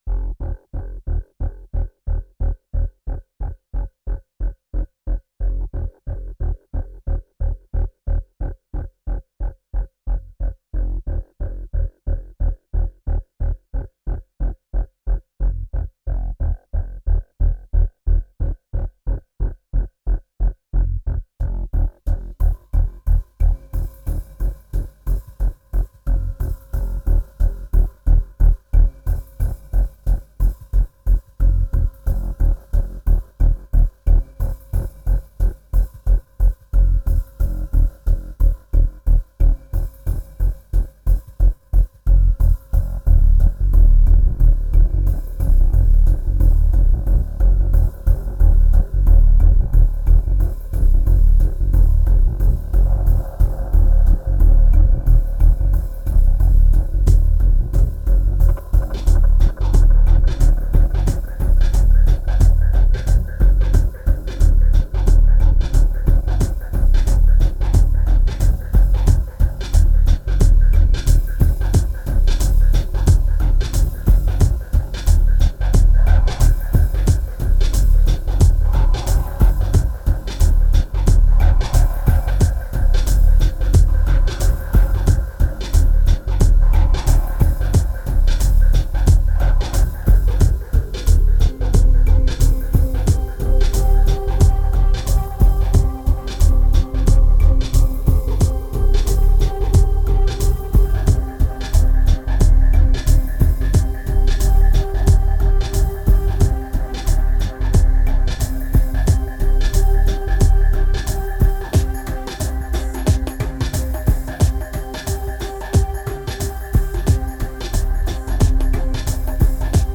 ambient album
2192📈 - 47%🤔 - 90BPM🔊 - 2010-11-10📅 - 31🌟